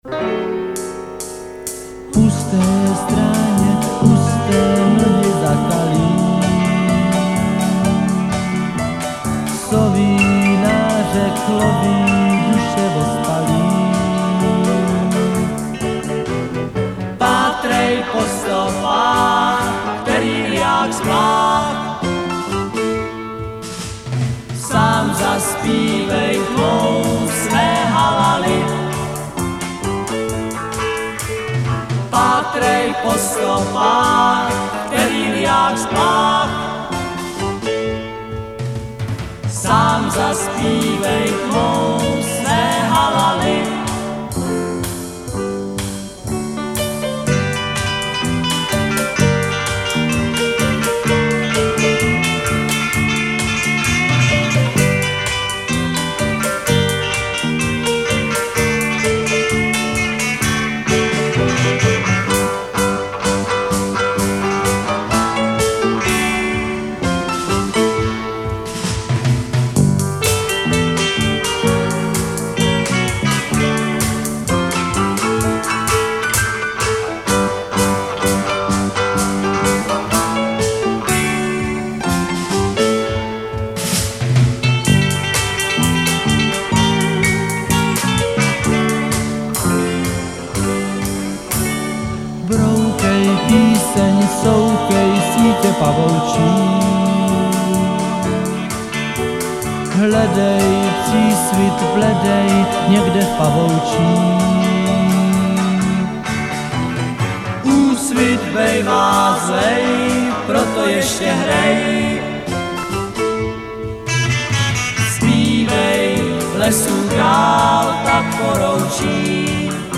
prázdninový pojízdný písničkový kabaret LUNA Text
Nahráno v rozhlasovém studiu ČR Plzeň 1968. zobrazit celý dlouhý text Rok